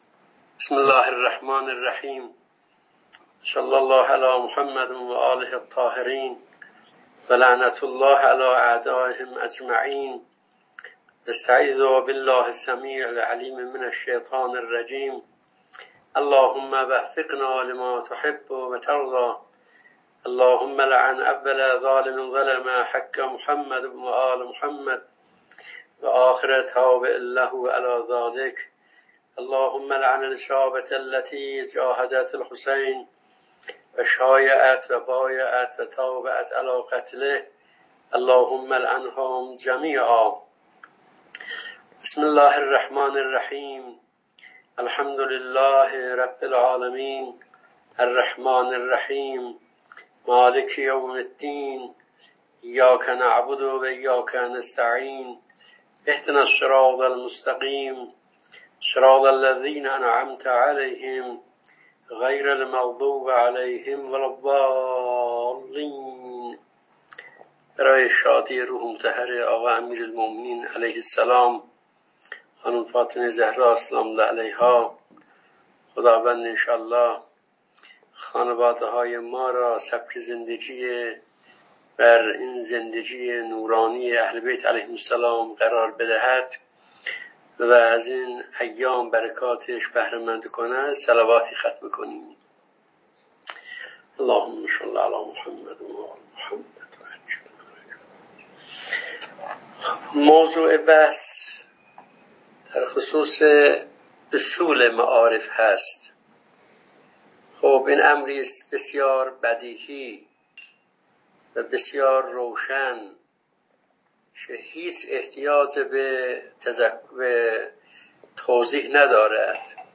جلسه مجازی دانشجویان فرهنگیان زنجان